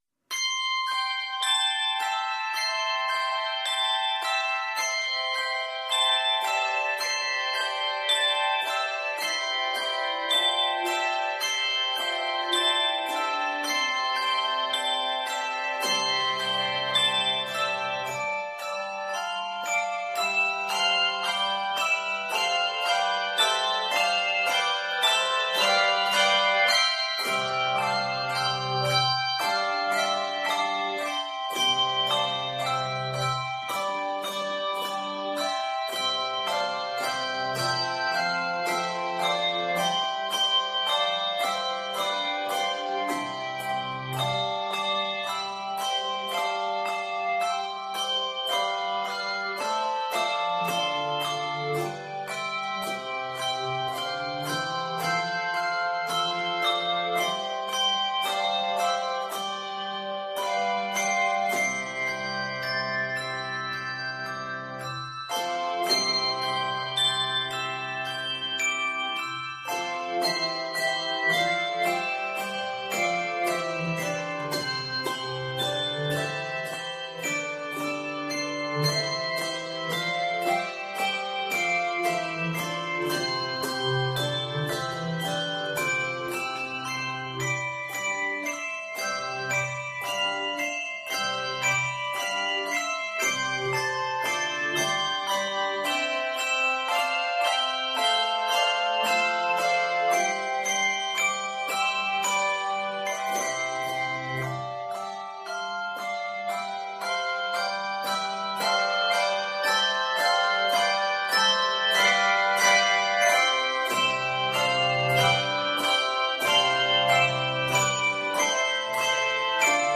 triumphant setting